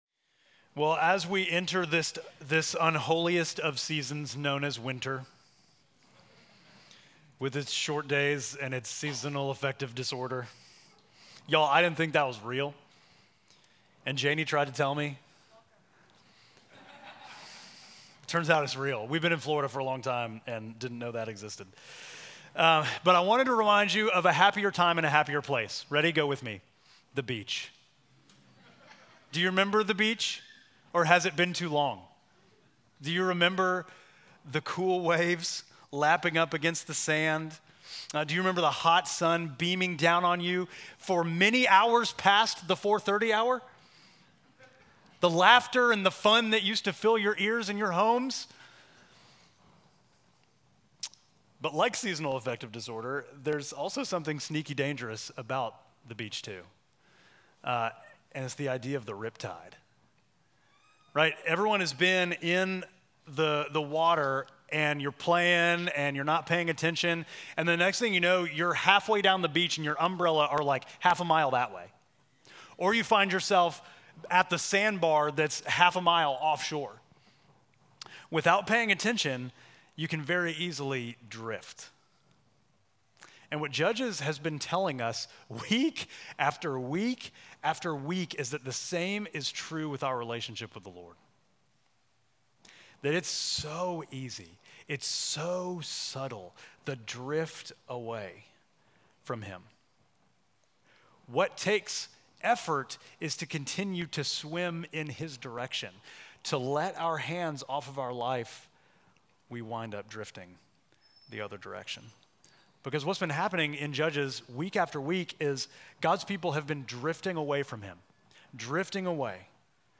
Midtown Fellowship Crieve Hall Sermons Jesus: The Savior King Nov 24 2024 | 00:30:20 1x 00:00 / 00:30:20 Subscribe Share Apple Podcasts Spotify Overcast RSS Feed Share Link Embed